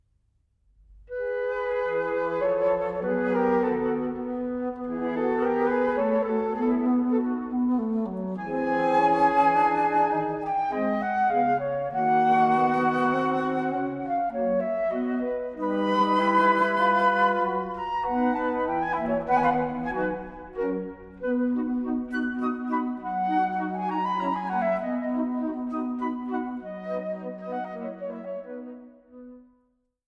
Piccoloflöte
Altflöte
Bassflöte
Kontrabassflöte